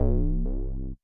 TEC Bass C0.wav